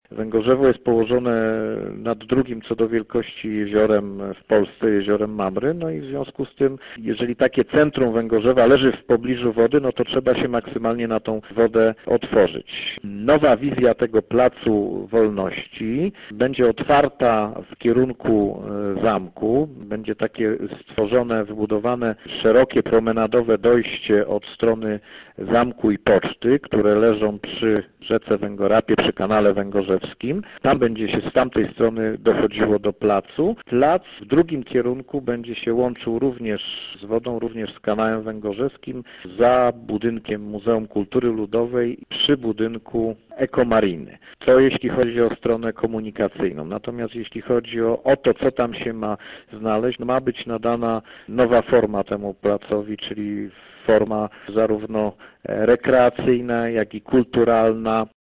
– Ten ważny punkt miasta zyska zupełnie nowe oblicze – mówi Krzysztof Piwowarczyk, burmistrz Węgorzewa.